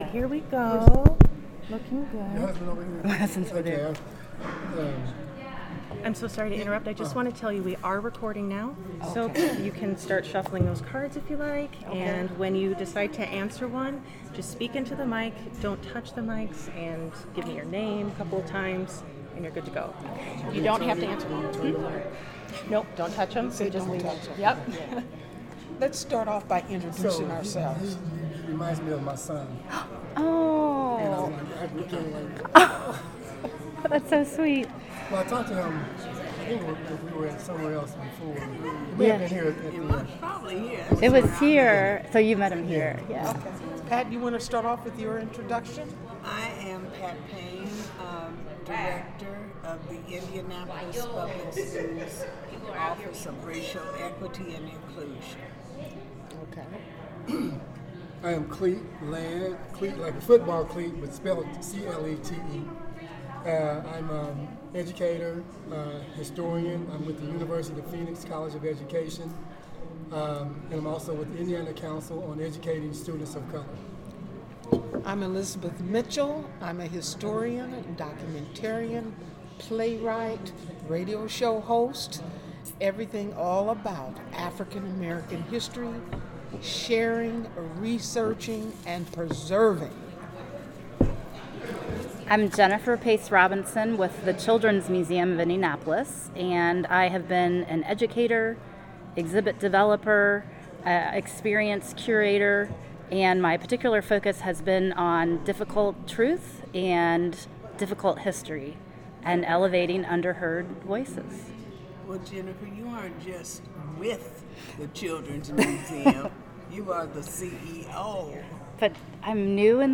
Type sound recording-nonmusical
Genre oral history